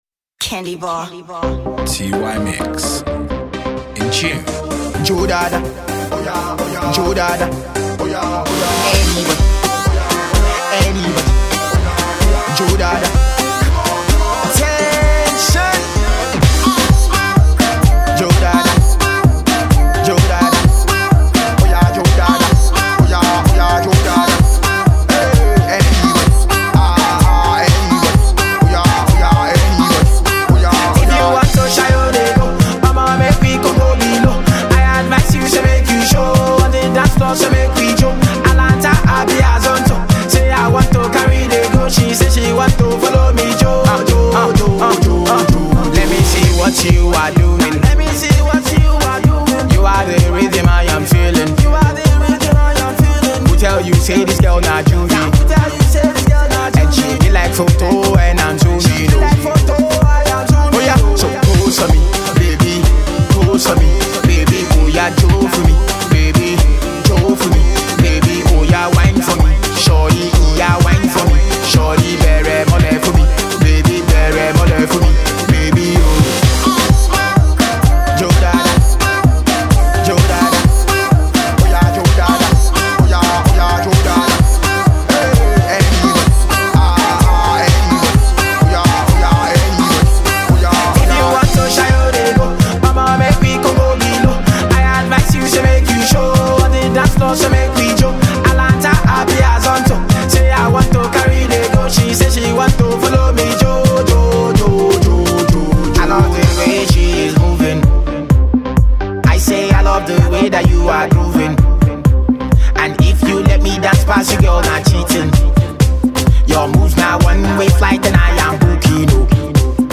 a funky Afro-Dance number
instigating a party.